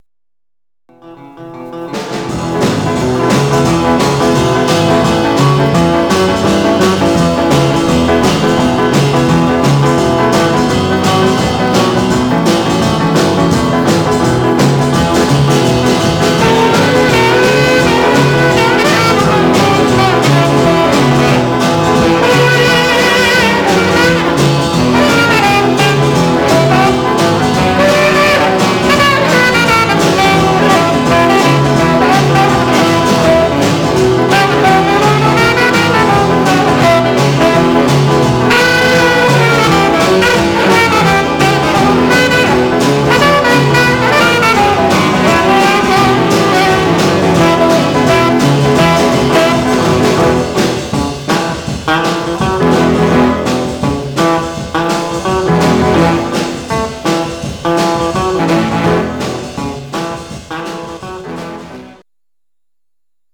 Mono
R & R Instrumental